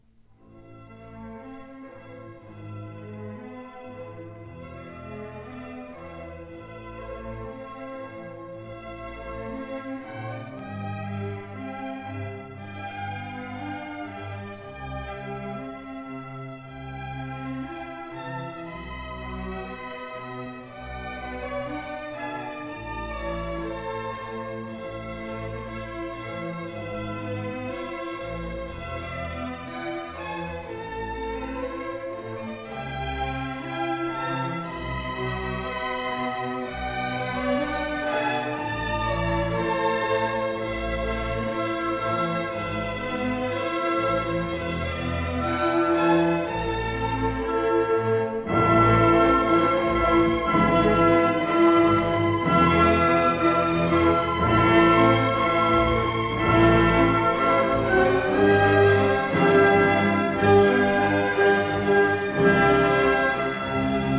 Stereo recording made in April 1961 at
Studio No.1, Abbey Road, London